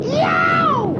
Worms speechbanks
Ow3.wav